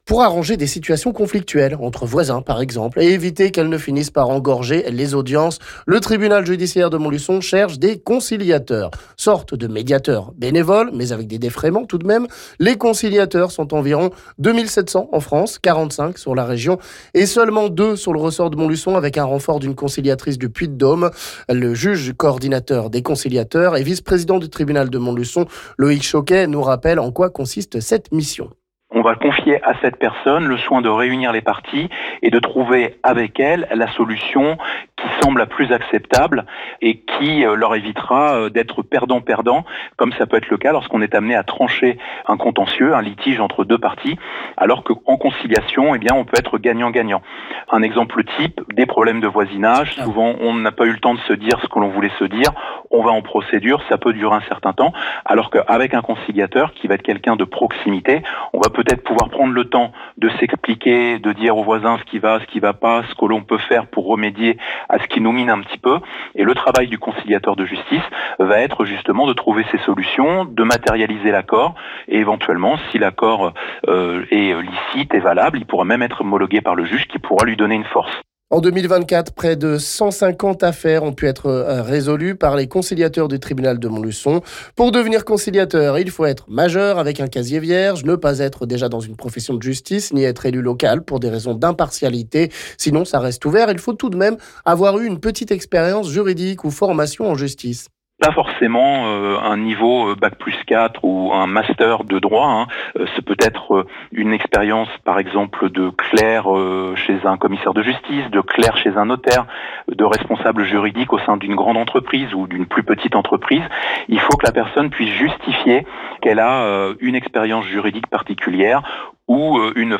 Sujet à écouter ici avec le vice-président du tribunal Loïc Choquet...